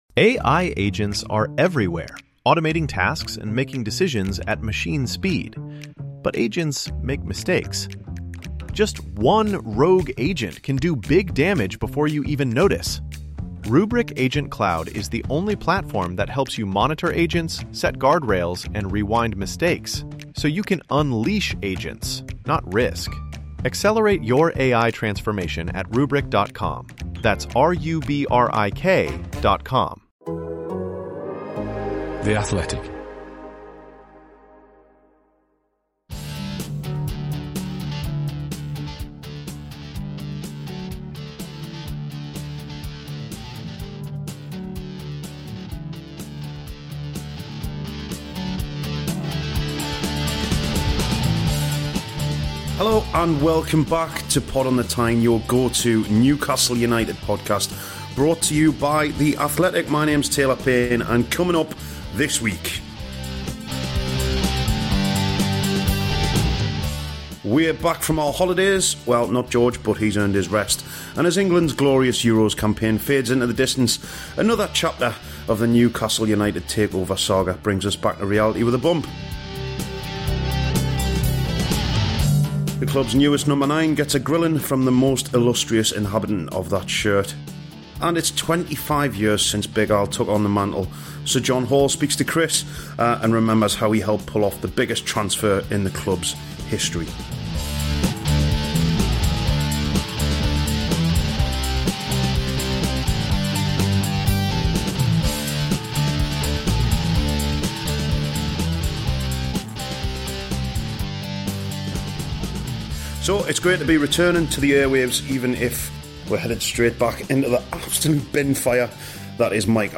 Sir John Hall was the Magpies' chairman at the time and he joins us to remember that remarkable moment.